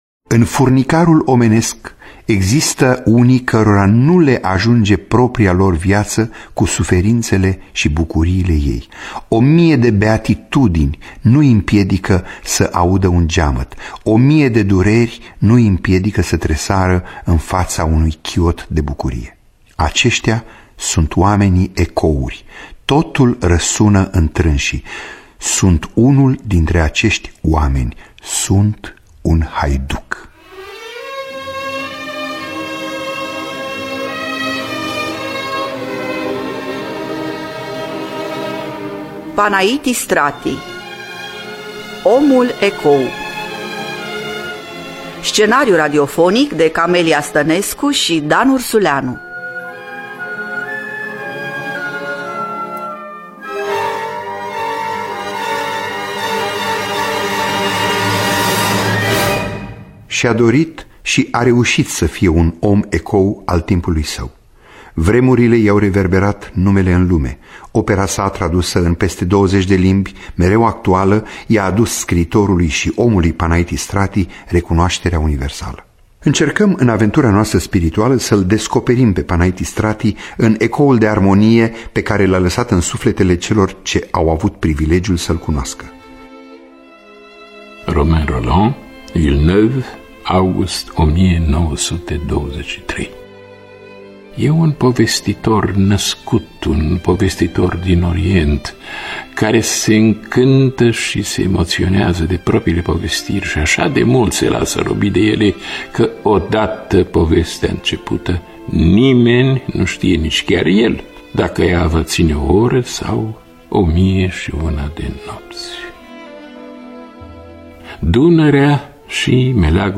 Omul Ecou (2010) – Teatru Radiofonic Online